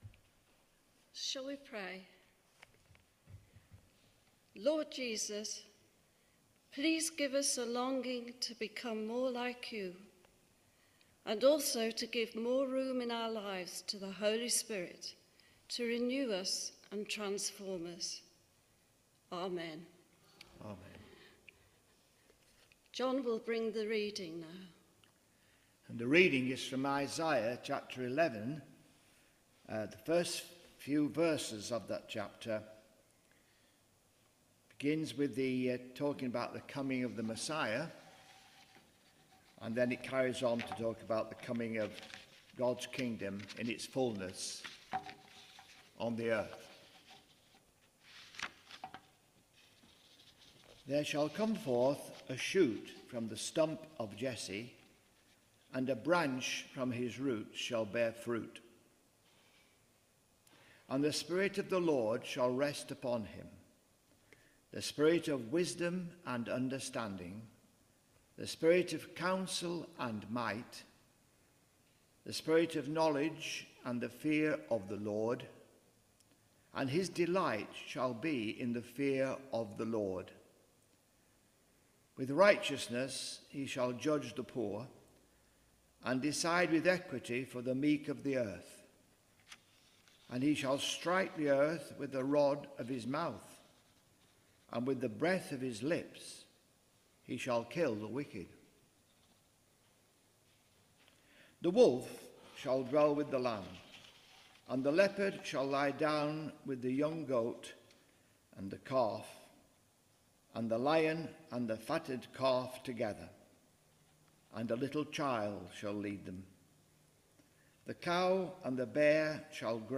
Media for Midweek Communion on Wed 11th Jun 2025 10:00 Speaker
Theme: Blessed by the Holy Spirit Sermon Search